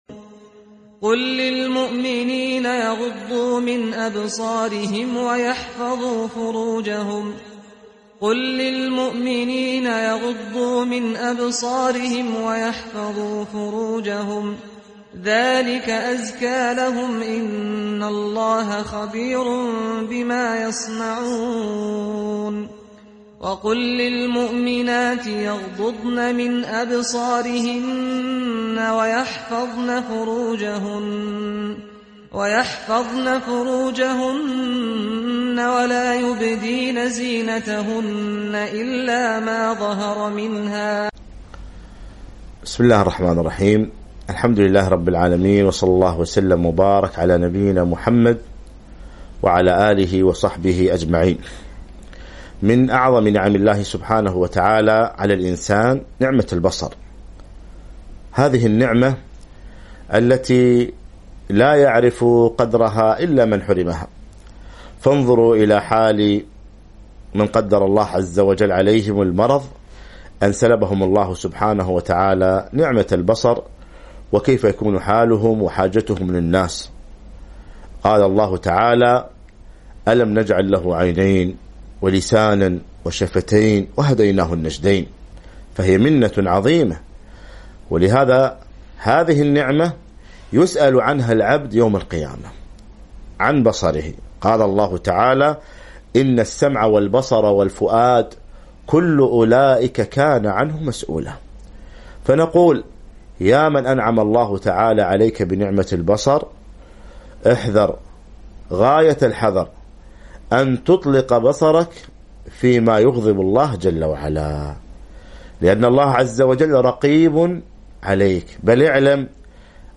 كلمة بعنوان قل للمؤمنين يغضوا من أبصارهم